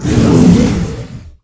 combat / creatures / dragon / he / hurt3.ogg
hurt3.ogg